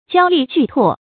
交詈聚唾 注音： ㄐㄧㄠ ㄌㄧˋ ㄐㄨˋ ㄊㄨㄛˋ 讀音讀法： 意思解釋： 謂一齊唾罵。